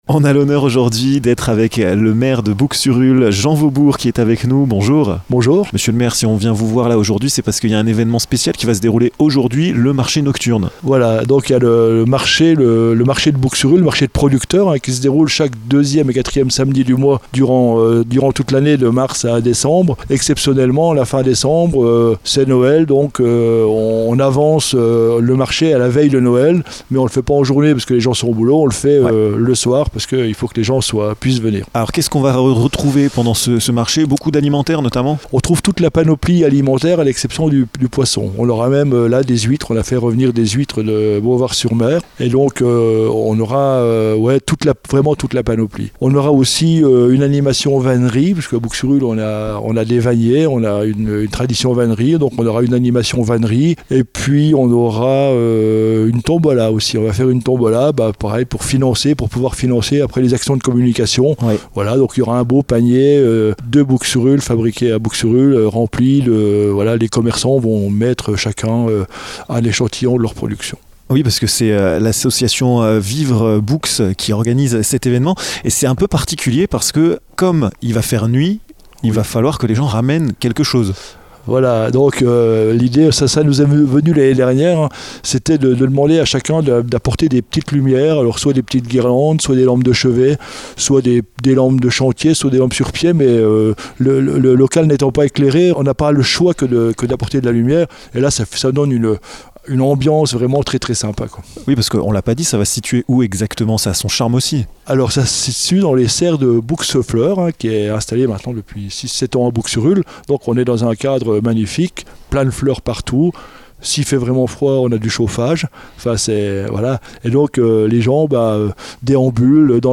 Ramenez vos lampes au marché nocturne de Bouxurulles ce vendredi 23 de 16H00 à 19H30. Nous avons eu la chance de croiser Jean Vaubourg, le maire de Bouxurulles, qui vous donne toutes les indications de ce bel événement dans cette interview!